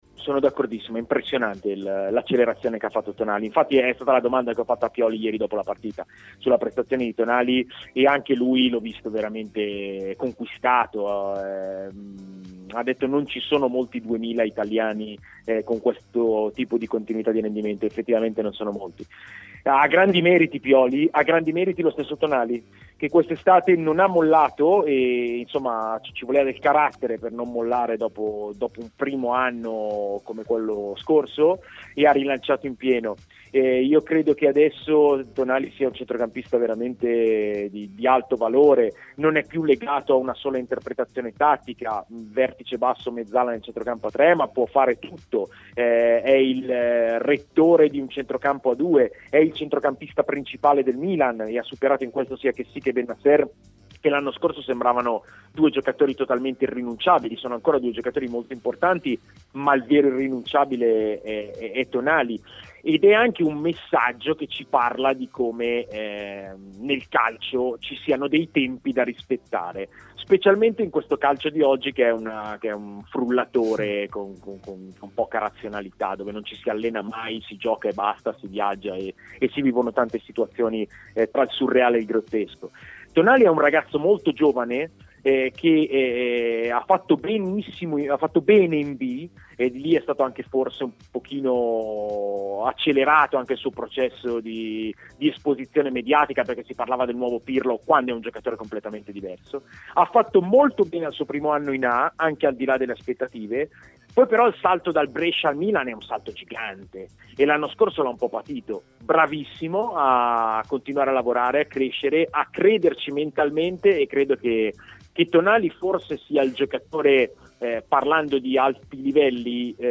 Stadio Aperto, trasmissione di TMW Radio